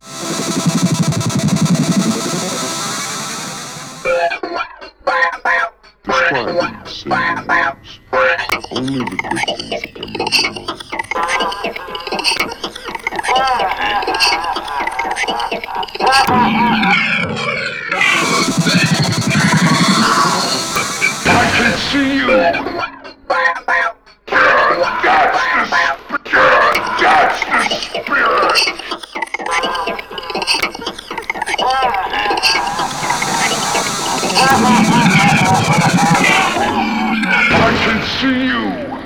Tecno (bucle)
repetitivo
ritmo
sintetizador